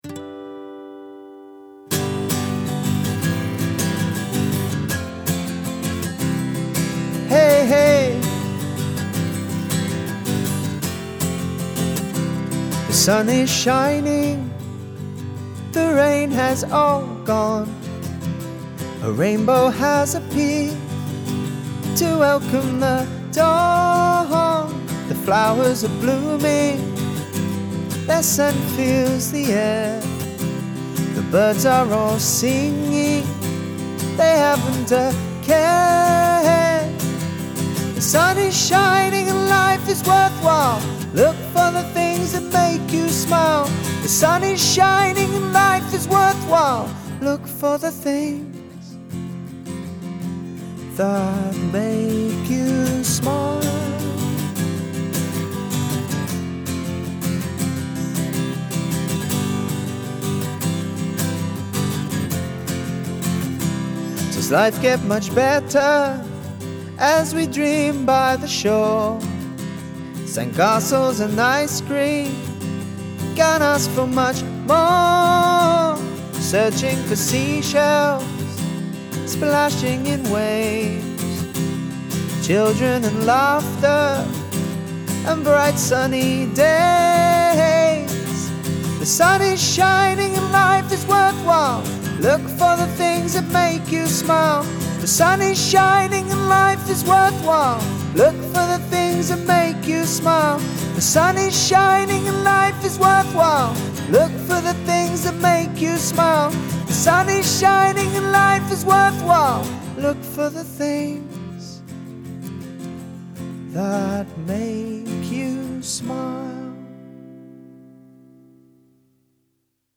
Once we’d written the song together, I took it into a music studio to record it.